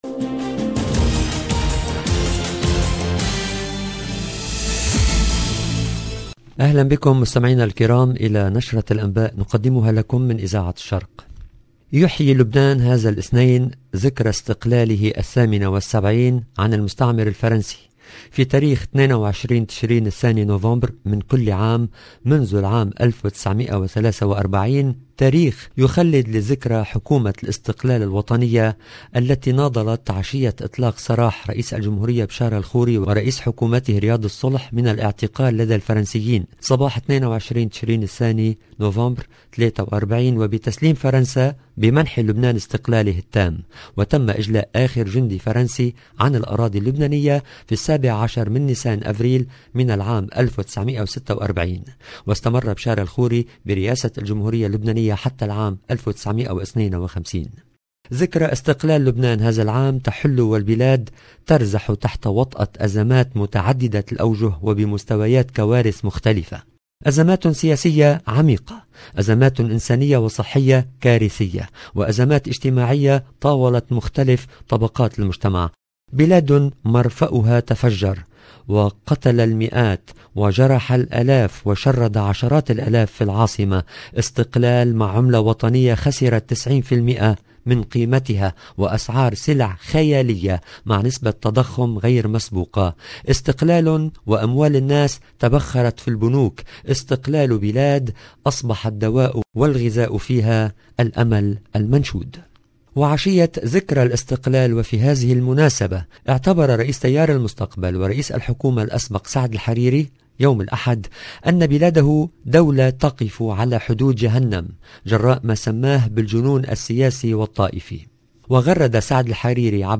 LE JOURNAL EN LANGUE ARABE DU SOIR DU 21/11/21